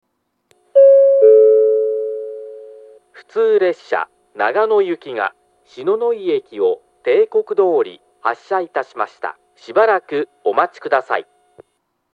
１番線篠ノ井駅発車案内放送 普通長野行の放送です。
列車が篠ノ井駅を発車すると流れます。
amori-1bannsenn-shinonoi-hassha.mp3